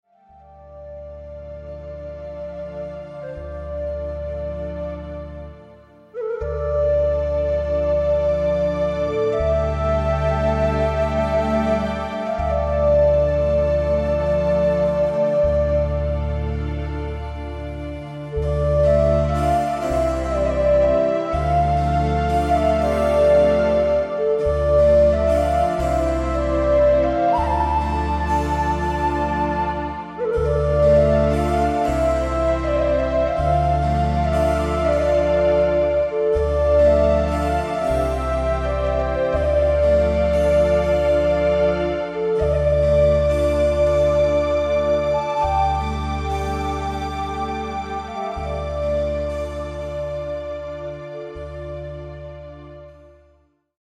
relaxing soothing music